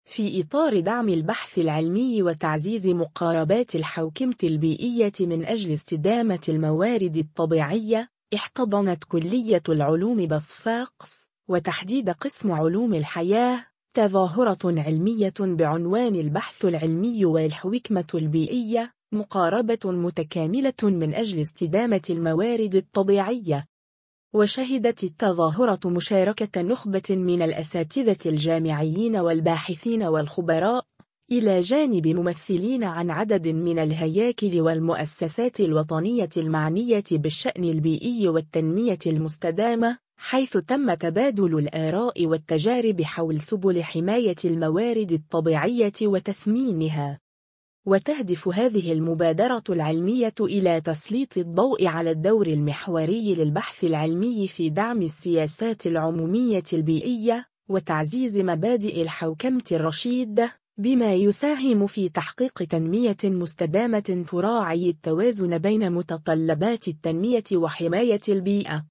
صفاقس : ندوة علمية حول البحث العلمي والحوكمة البيئية [فيديو]
في إطار دعم البحث العلمي وتعزيز مقاربات الحوكمة البيئية من أجل استدامة الموارد الطبيعية، احتضنت كلية العلوم بصفاقس، وتحديدًا قسم علوم الحياة، تظاهرة علمية بعنوان «البحث العلمي والحوكمة البيئية: مقاربة متكاملة من أجل استدامة الموارد الطبيعية».